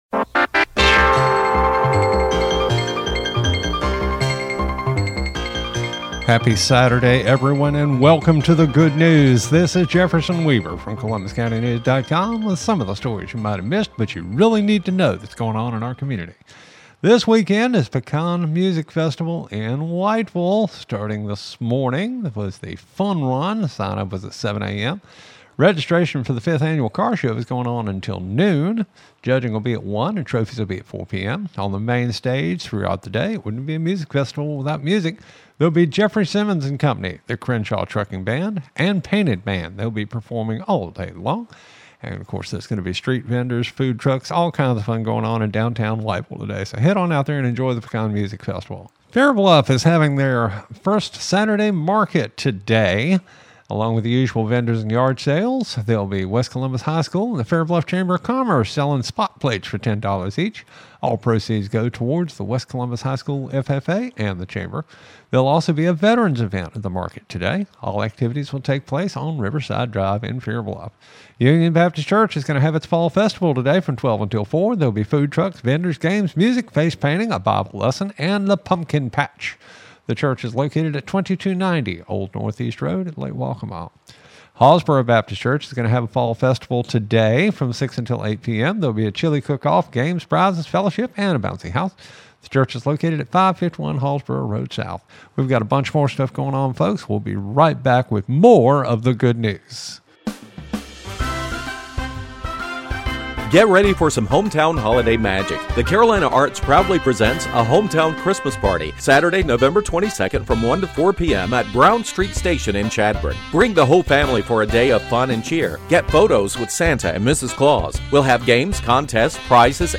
The Good News — Weekend Audio 🔊 Report for November 1, 2025 (Press Play for the Audio)
Local Radio Made for Columbus County and Available Worldwide